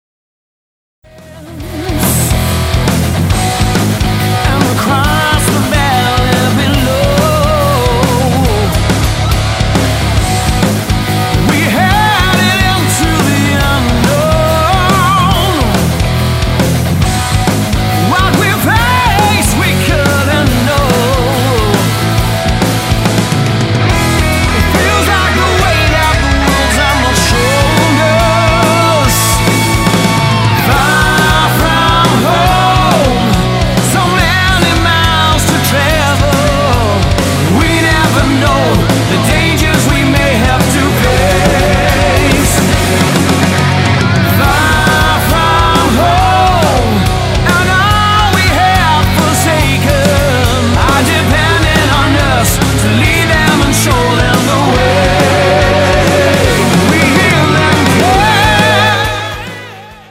Bass
Guitars
Drums
Keyboards
12 tracks packed with monstrous riffs and soaring melodies